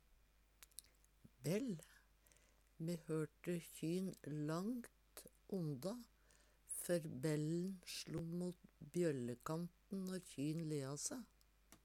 Tilleggsopplysningar Lyden i hørte er ikkje rein r- lyd.